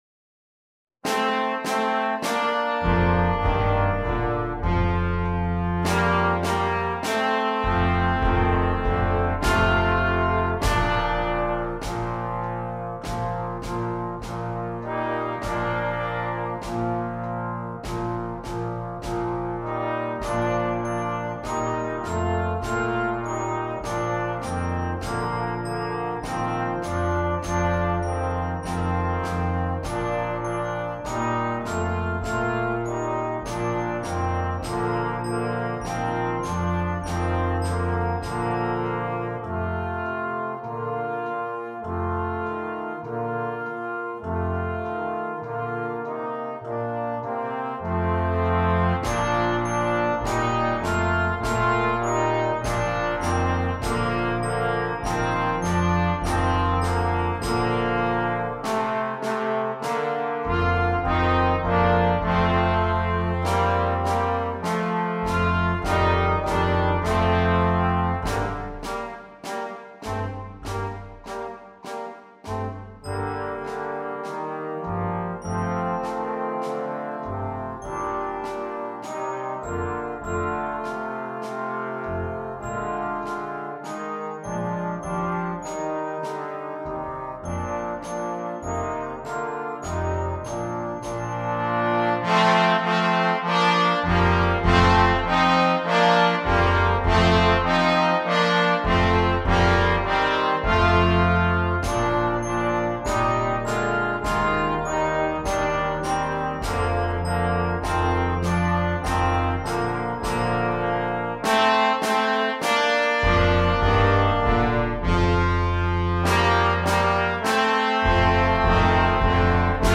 Beginning & Jr. Band Instrumentation
Beginning Band